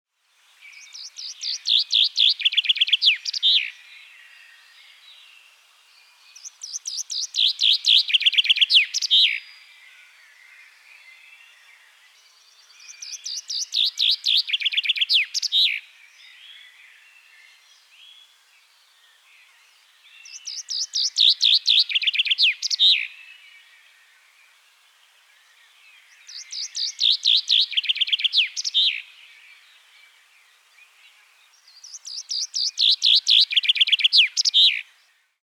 Wer singt oder ruft hier?
Vogel 7
Die Tierstimmen sind alle aus dem Tierstimmenarchiv des Museum für Naturkunde - Leibniz-Institut für Evolutions- und Biodiversitätsforschung an der Humboldt-Universität zu Berlin
MH12_Vogel7.mp3